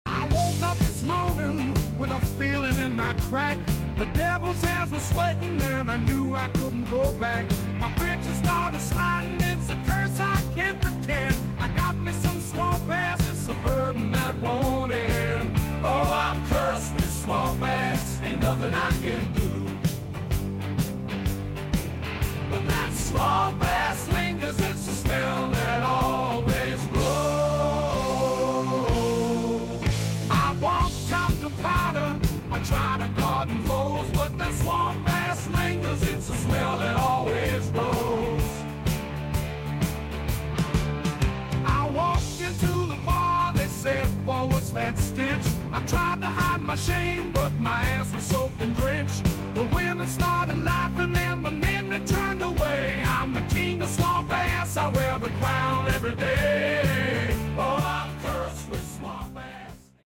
during a particularly humid studio session